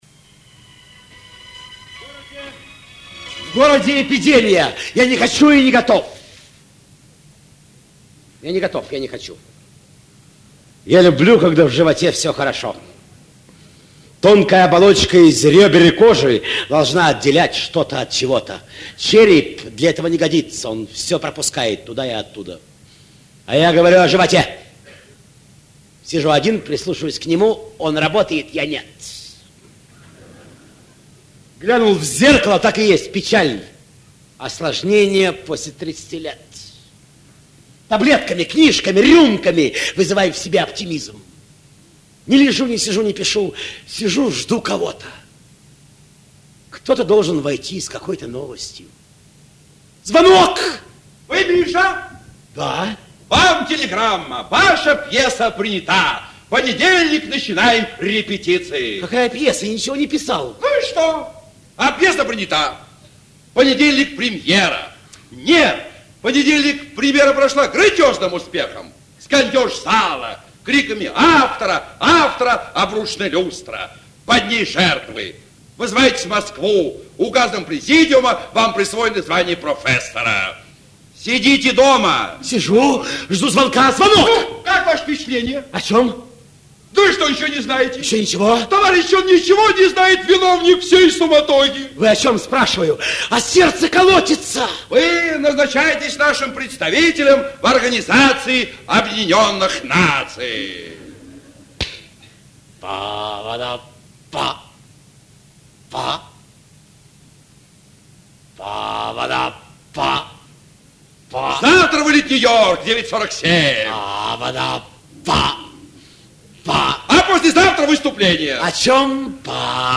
Продолжение редких записей миниатюр в исполнении Виктора Ильченко и Романа Карцева. 02 - В.Ильченко-Р.Карцев - Жду звонка